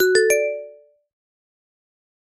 Звуки уведомления чата
Звук оповещения сообщения в хелп-чате (техподдержка) (00:02)